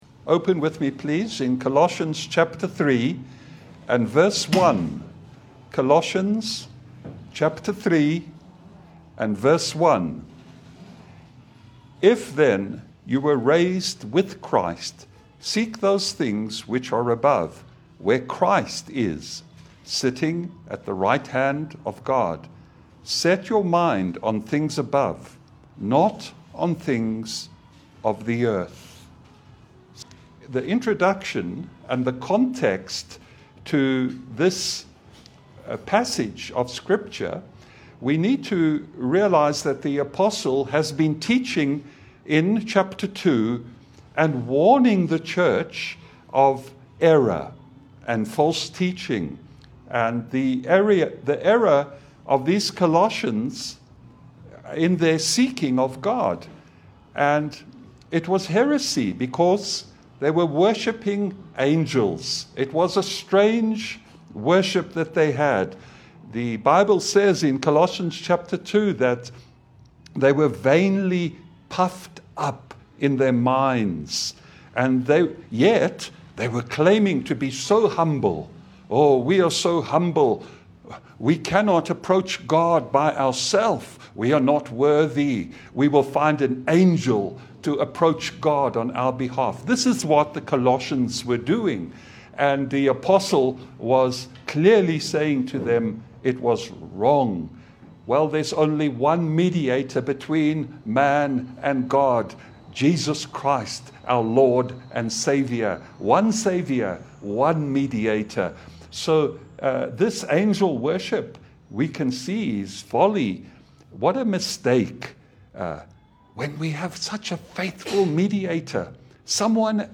A Christ‑centred sermon urging believers to set their minds on things above, grounded in the sufficiency of Christ.
Service Type: Sunday Bible fellowship